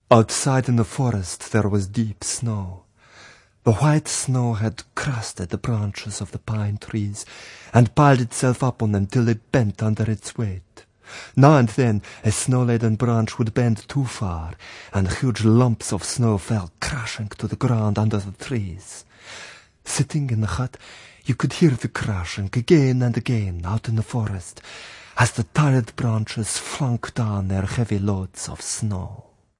Reading 5: